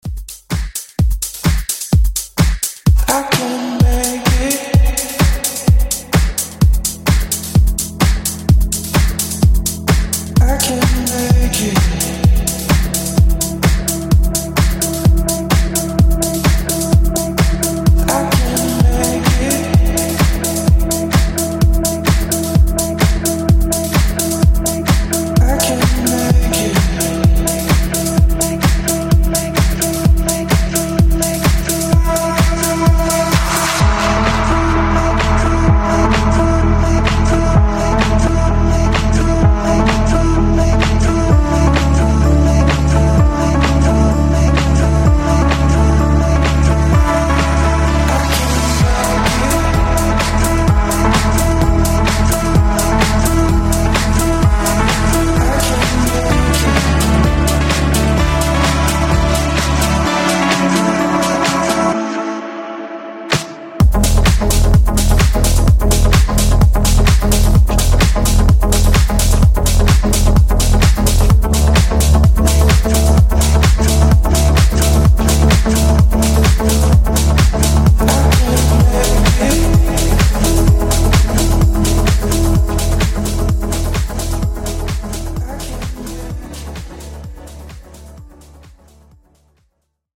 Genres: DANCE , EDM , RE-DRUM
Clean BPM: 128 Time